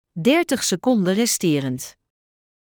Alle functies van deze airfryer worden uitgesproken met een duidelijke vrouwenstem.
103.-30-SECONDS-REMAINING.mp3